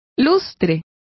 Complete with pronunciation of the translation of luster.